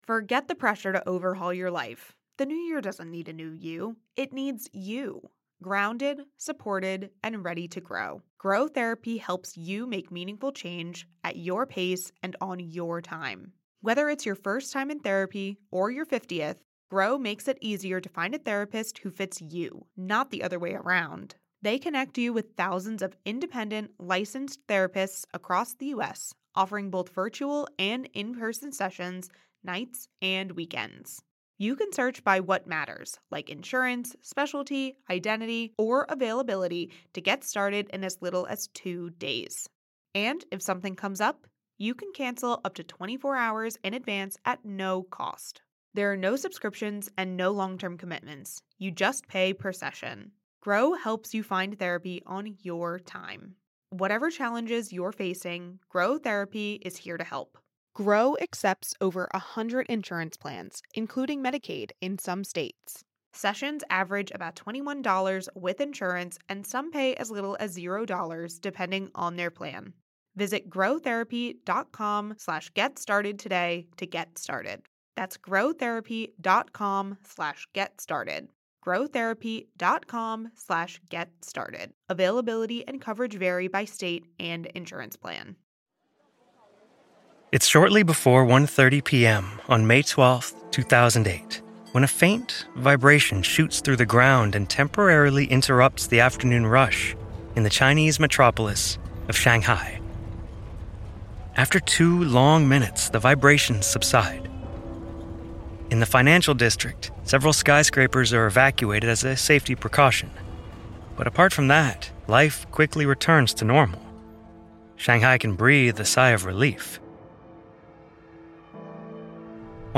Sound design